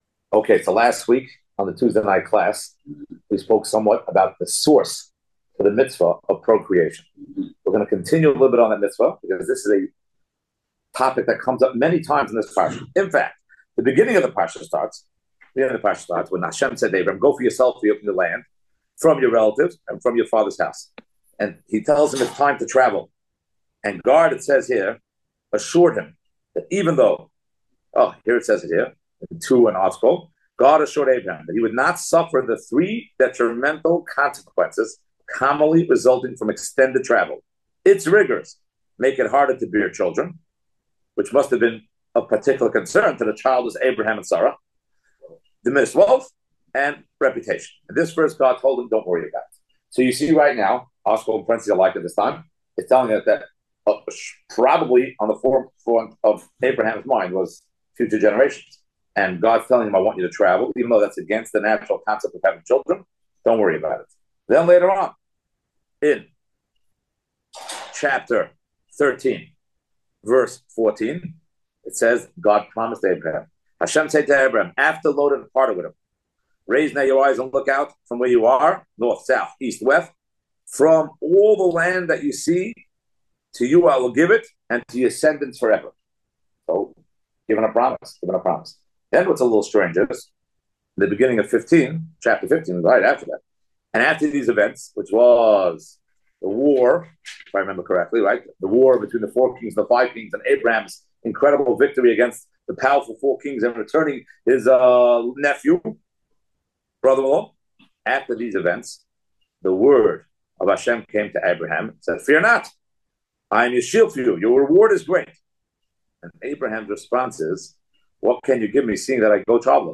(Note: sound is a bit out of sync)